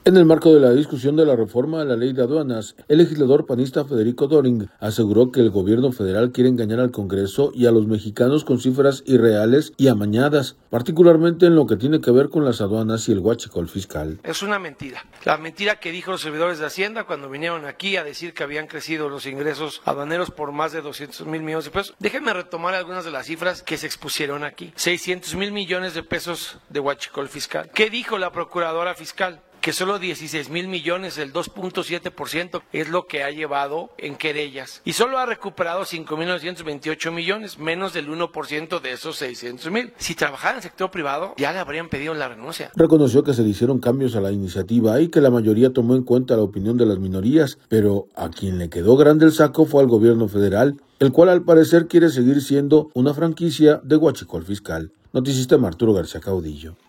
En el marco de la discusión de la reforma a la Ley de Aduanas en comisiones de la Cámara de Diputados, el legislador panista, Federico Döring, aseguró que el Gobierno Federal quiere engañar al Congreso y a los mexicanos con cifras irreales y amañadas, particularmente en lo que tiene que ver con las aduanas y el huachicol fiscal.